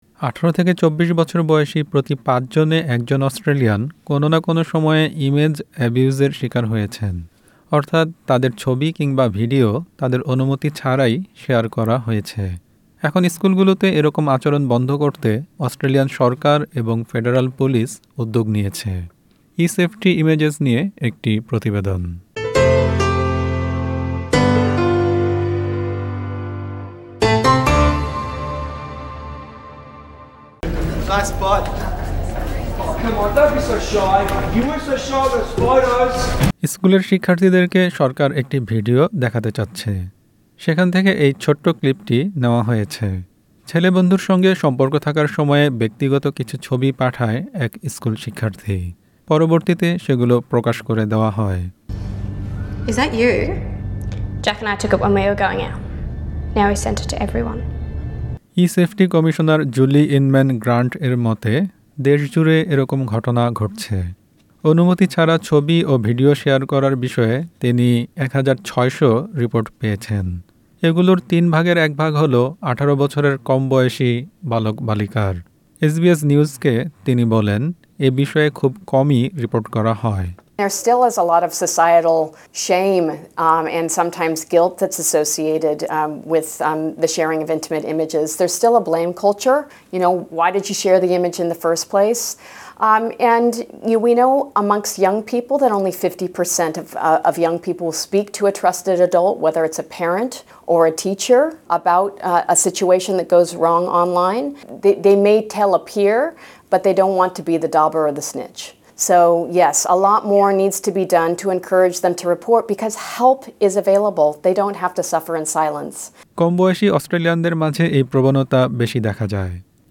প্রতিবেদনটি বাংলায় শুনতে উপরের অডিও প্লেয়ারটিতে ক্লিক করুন।